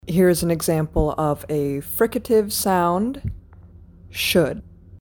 fricatives the mic registers when you attach the pop filter, but you don’t need it to use the mic.
fricatives-effect-microphone_01.mp3